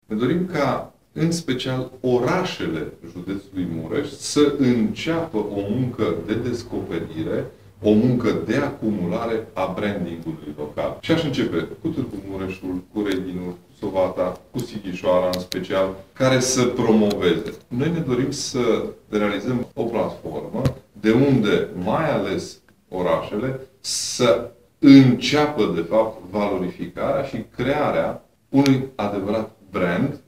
Conferința internațională „JUDEȚUL MUREȘ – BRAND LOADING” a fost dedicată unor invitați din țară și din străinătate, care au vorbit despre felul în care văd județul Mureș și despre oportunitățile pe care acesta le are, pentru crearea unui brand de regiune.
Președintele Consiliului Județean Mureș, Ciprian Dobre.